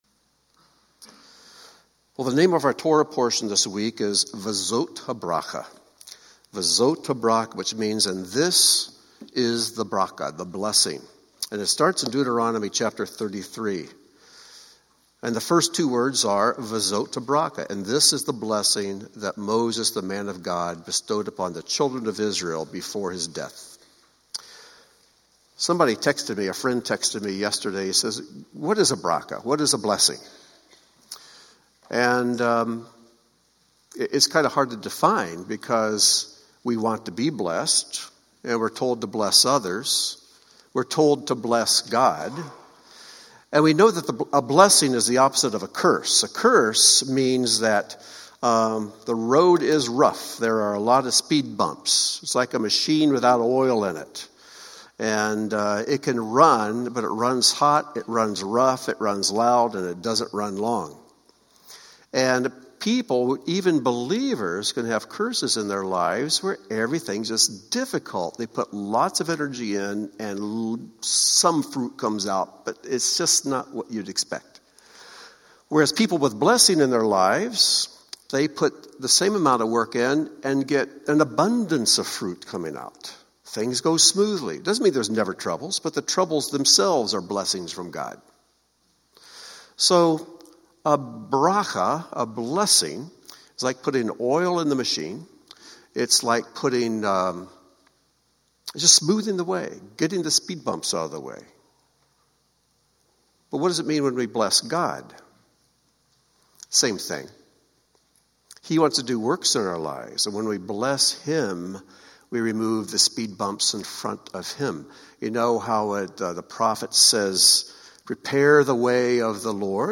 Join Beth Tikkun for an abbreviated teaching on the final Torah portion of the year, Ve'zot Habrachah.